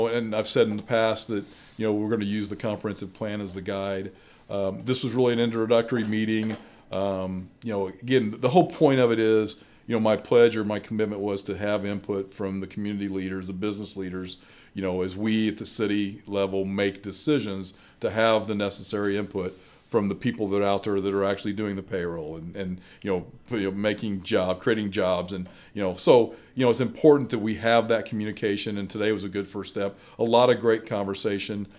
Speaking on our podcast, “Talking About Vandalia,” Mayor Doug Knebel says he believes their first meeting went very well.